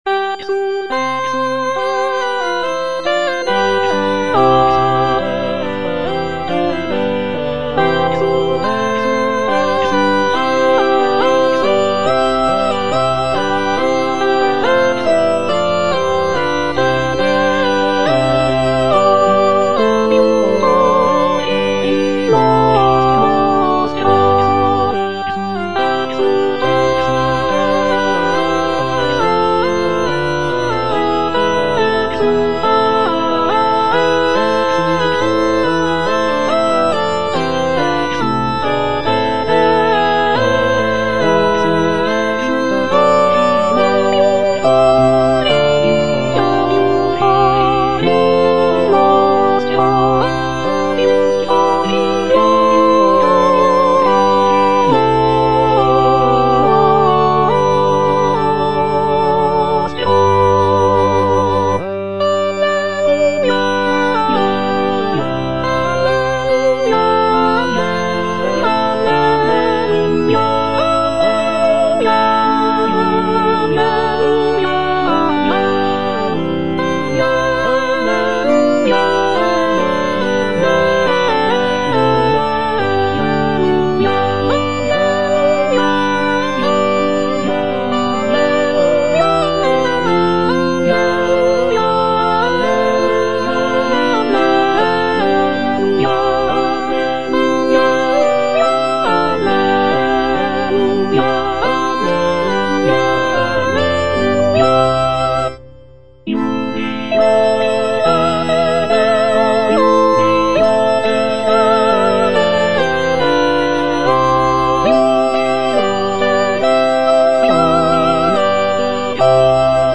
sacred choral work
Soprano (Emphasised voice and other voices) Ads stop